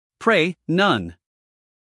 英音/ preɪ / 美音/ preɪ /